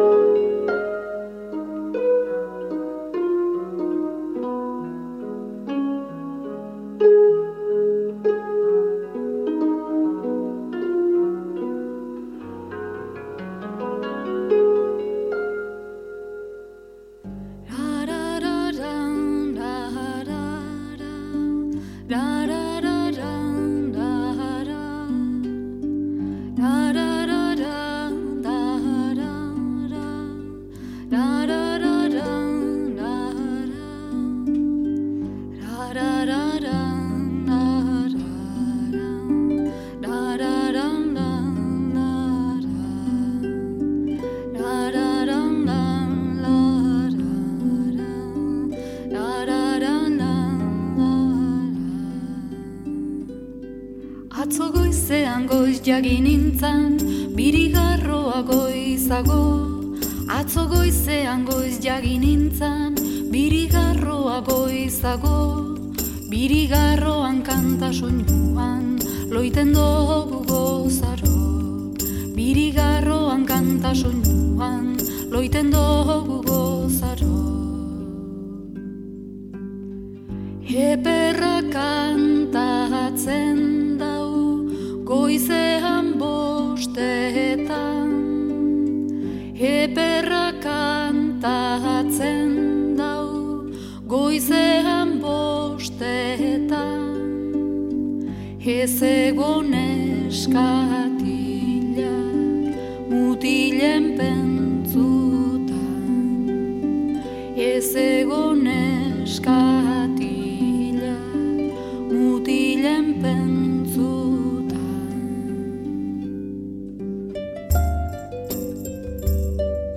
harp en zang. De experimenteel-electronische band